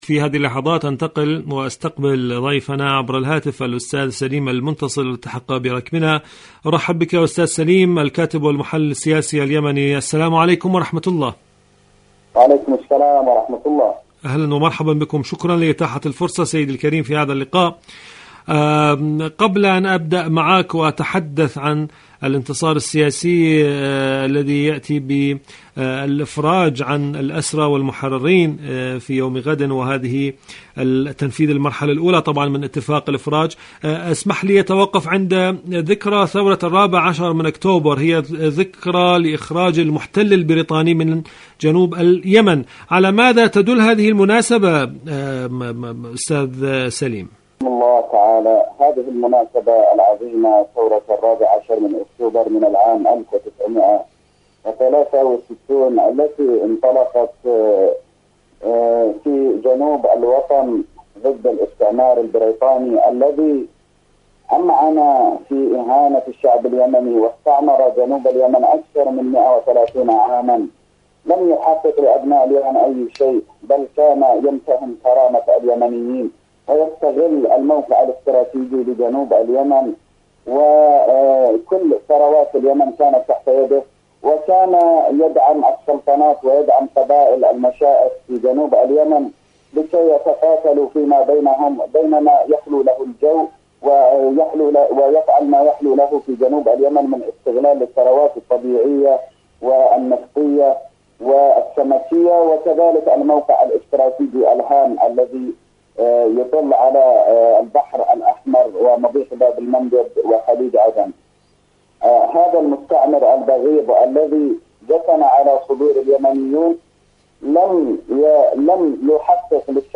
مقابلات